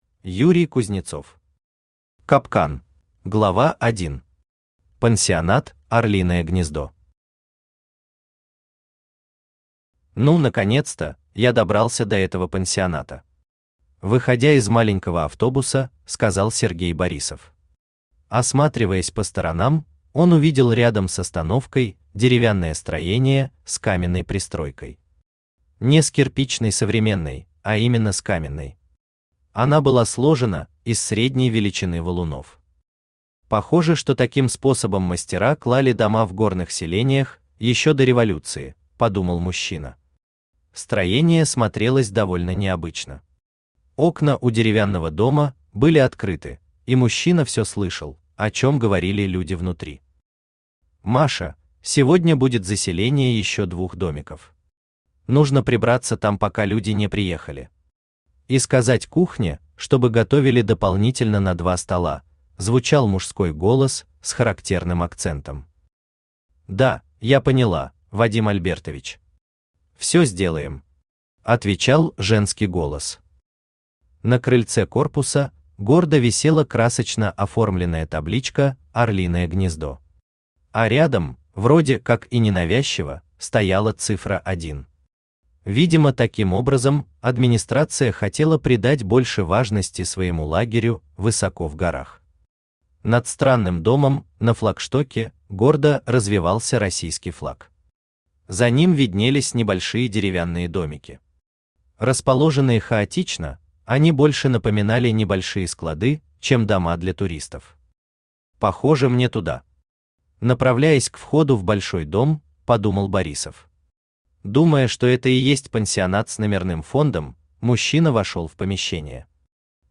Аудиокнига Капкан | Библиотека аудиокниг
Aудиокнига Капкан Автор Юрий Юрьевич Кузнецов Читает аудиокнигу Авточтец ЛитРес.